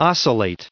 added pronounciation and merriam webster audio
553_oscillate.ogg